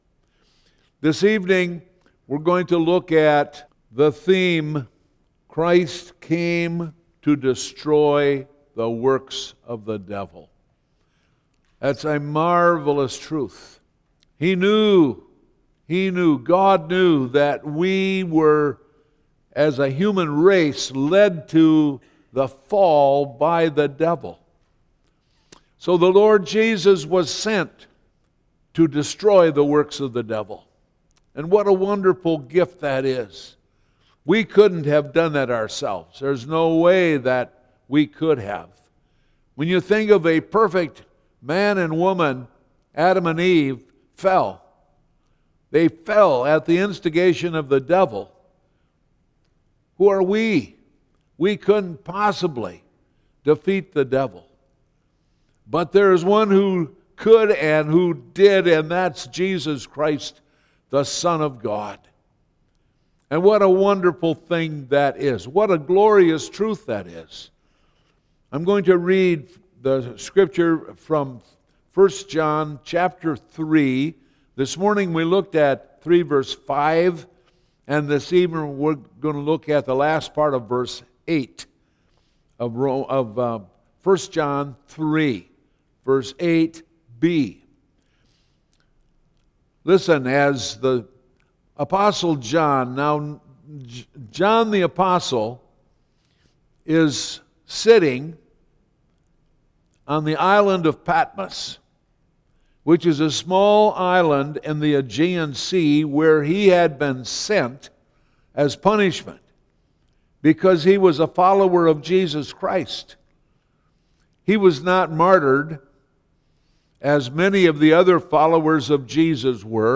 Various Sermons